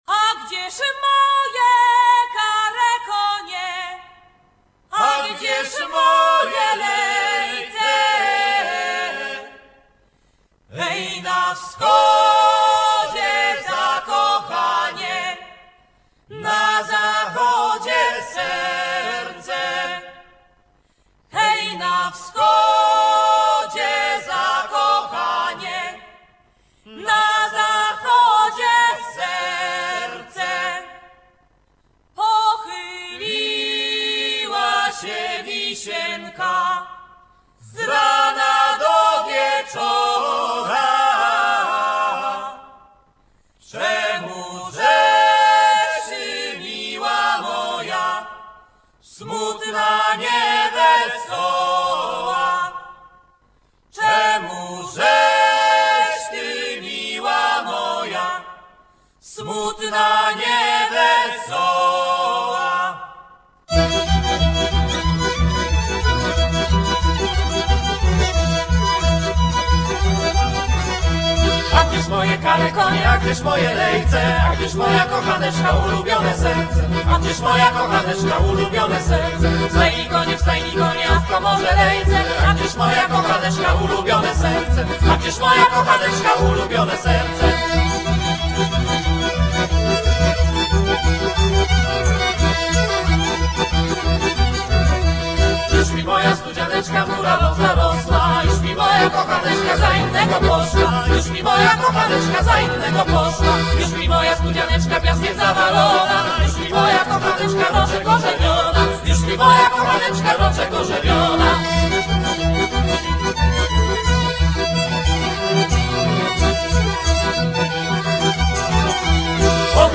violin
viola
percussion
accordion, lyre, double bass). Originally from Poznan, they play village music from all regions of Poland.